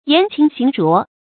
言清行濁 注音： ㄧㄢˊ ㄑㄧㄥ ㄒㄧㄥˊ ㄓㄨㄛˊ 讀音讀法： 意思解釋： 言辭高潔，行為卑污。